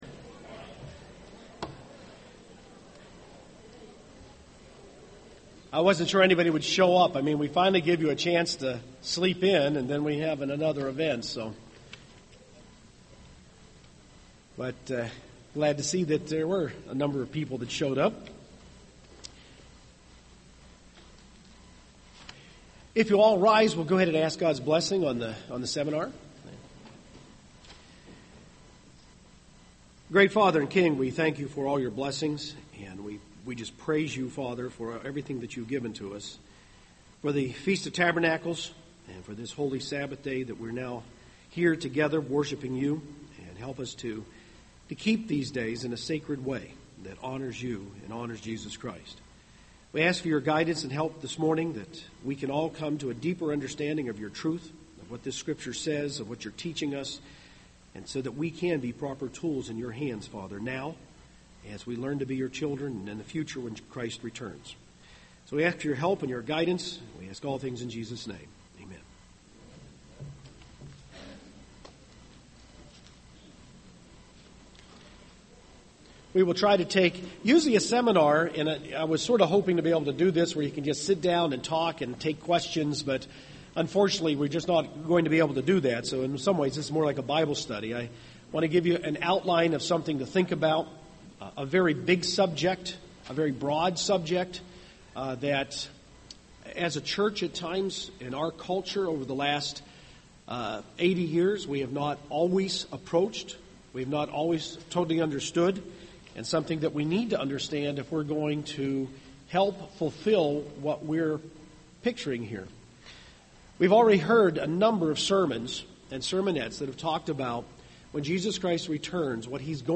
This sermon was given at the Gatlinburg, Tennessee 2011 Feast site.